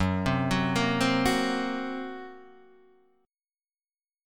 F# Major Flat 5th